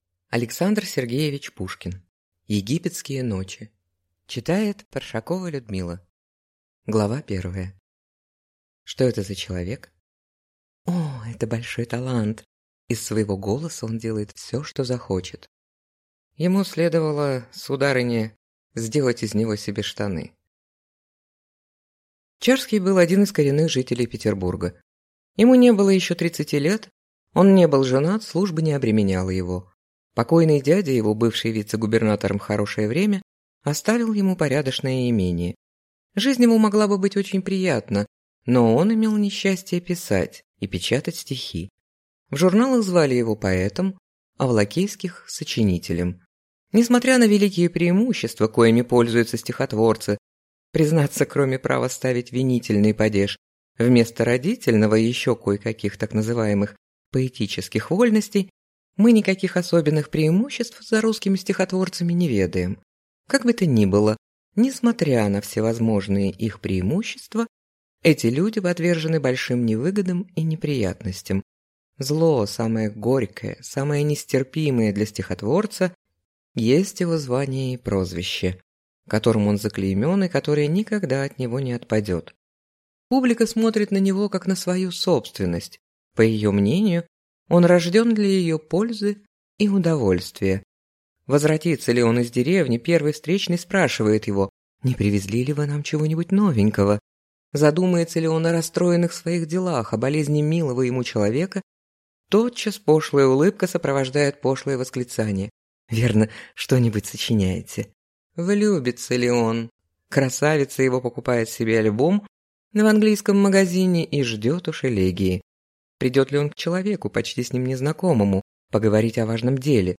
Аудиокнига Египетские ночи | Библиотека аудиокниг